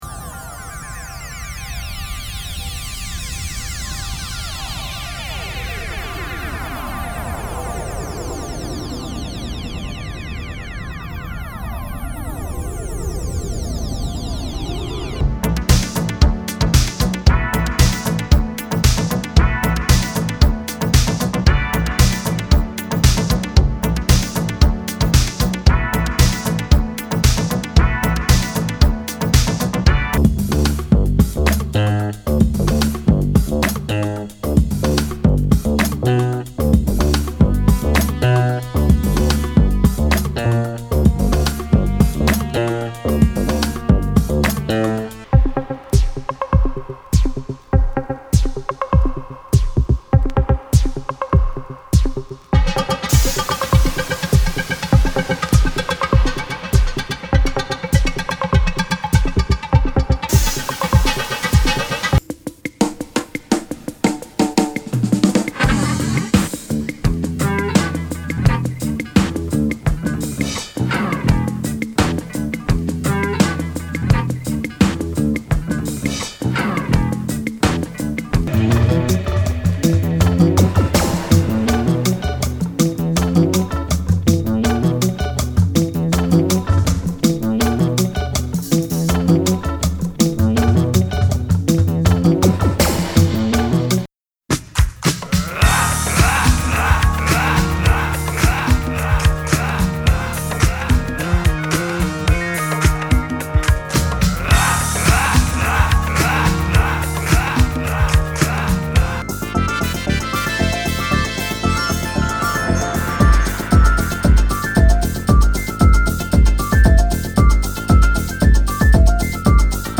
J-Rock / Pops 90's~00's
*サンプル音源は一曲１５秒位づつのアルバム・ダイジェスト版です。